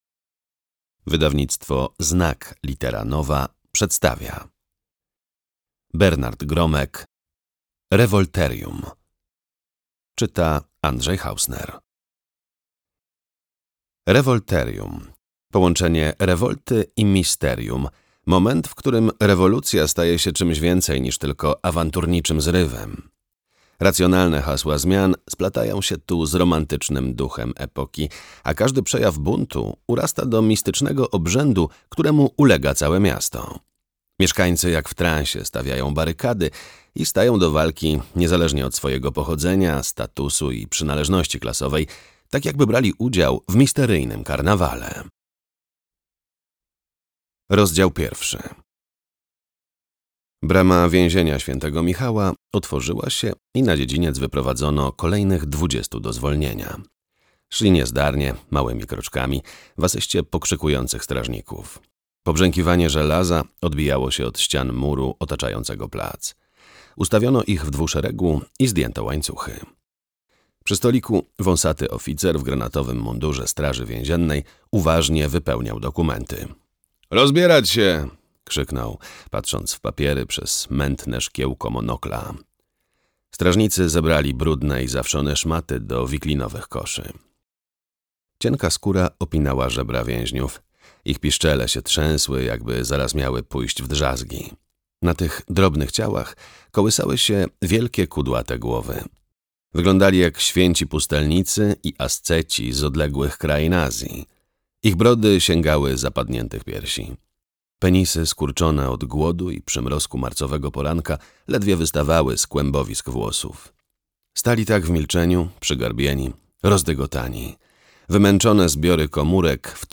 audiobook + książka